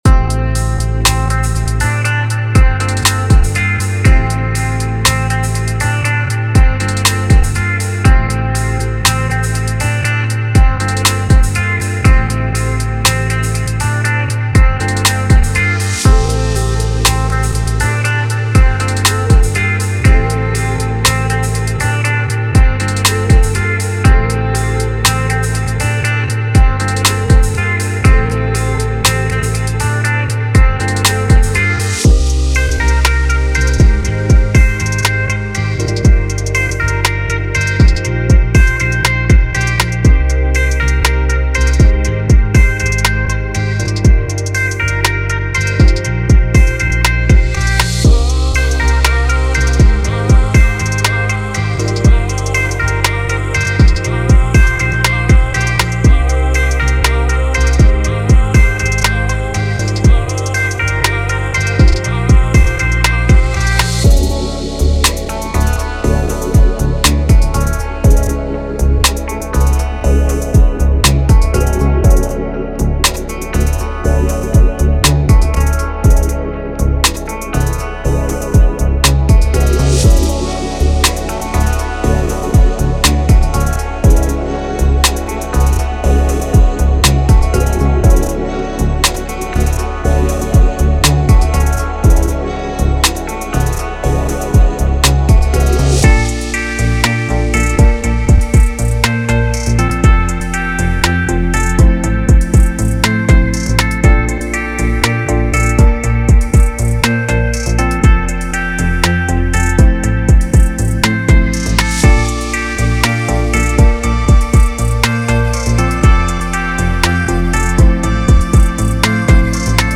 Bass Loops: Smooth and groovy basslines that lay down the perfect foundation for your tracks.
Drum Loops: From tight, punchy drums to laid-back grooves, our drum loops provide the ideal rhythm section for your soulful creations.
Keyboard Loops: Lush electric pianos, vibrant organs, and jazzy keys that add melodic richness and harmonic sophistication.
Guitar Loops: Warm, expressive guitar riffs and chords that bring a human touch and organic feel to your music.
Vocal Loops: Soulful vocal snippets and harmonies that inject emotion and depth into your tracks.
Horn Loops: Dynamic brass sections that add a touch of classic soul and funk flair.